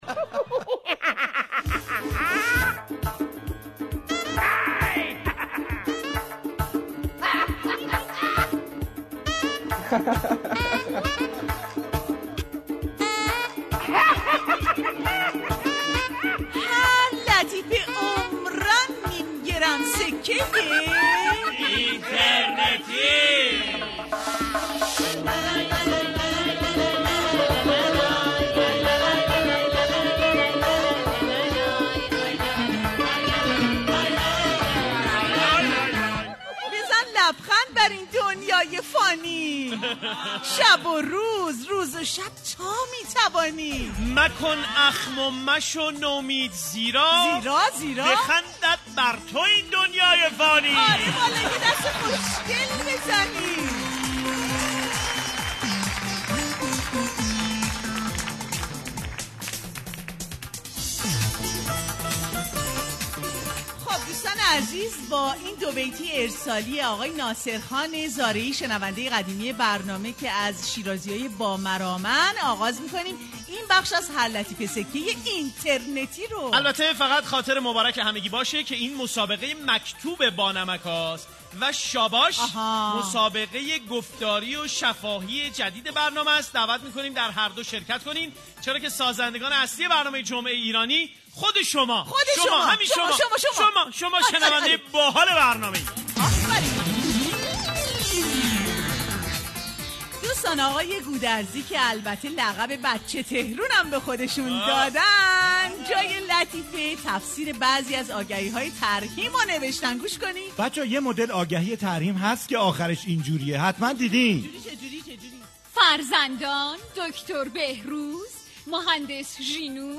• دفتر خاطرات, شاعر یه ربع یه ربع, لطیفه های اینترنتی, جک های جدید, اخبار در 60 ثانیه, مرتضی احمدی, ترانه های شاد, جمعه ایرانی, برنامه طنز رادیو ایران, دانلود جمعه ایرانی, رادیو ایران, لطیفه های با مزه, موزیک های شاد, پ ن پ, آقای مثبت اندیش, گیر بازار